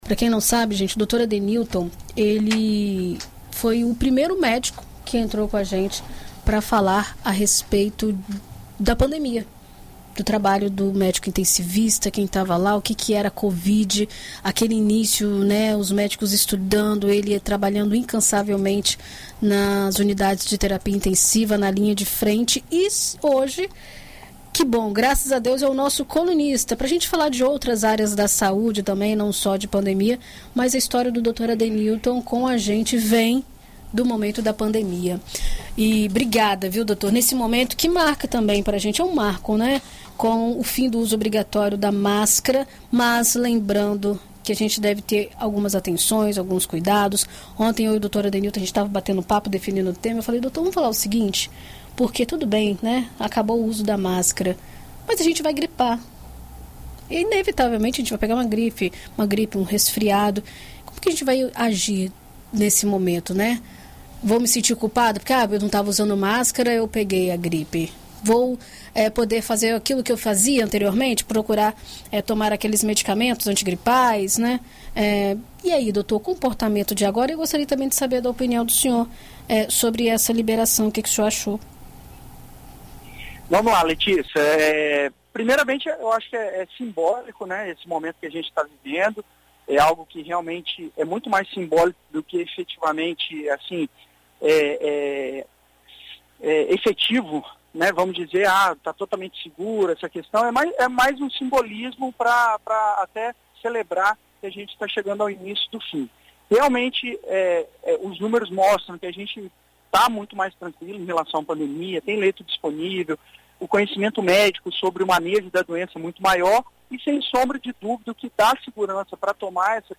Na coluna Visita Médica desta quinta-feira (07), na BandNews FM Espírito Santo,